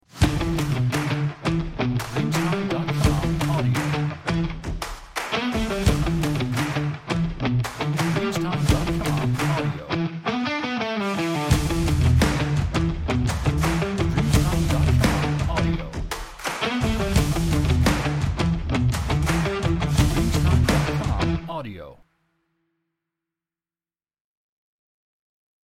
Stomp Rock